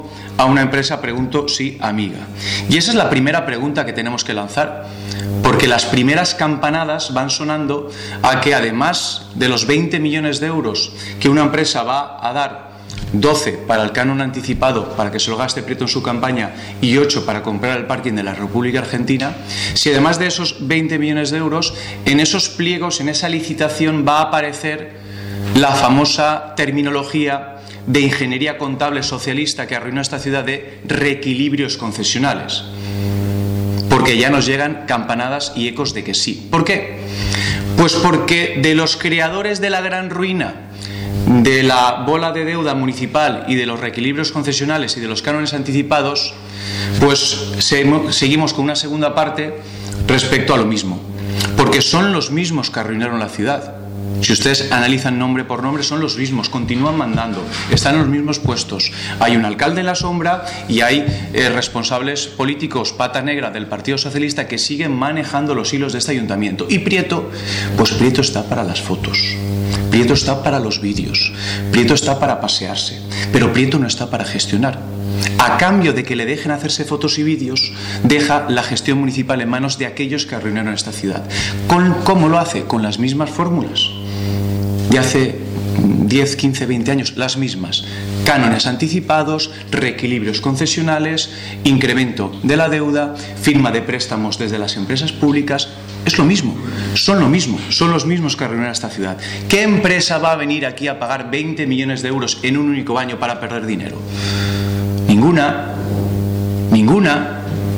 aquí (audio) al concejal Víctor Soler.